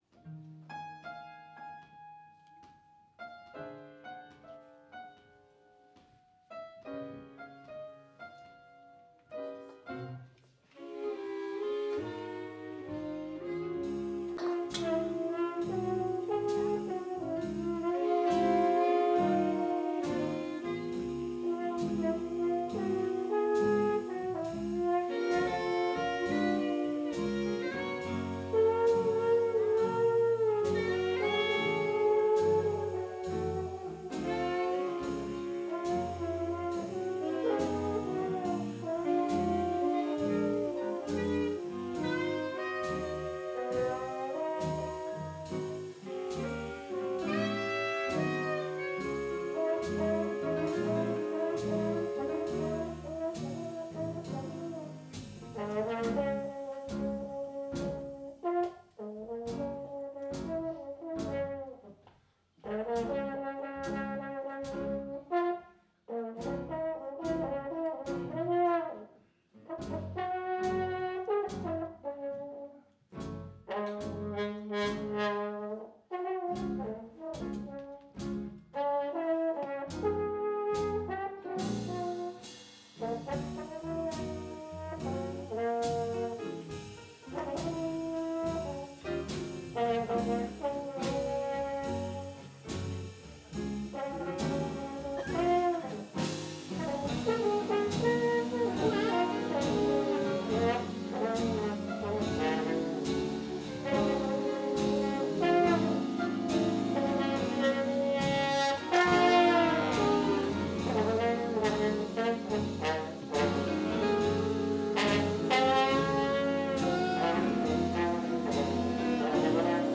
Udstyret er én digital stereo mikrofon, ikke en studieoptagelse !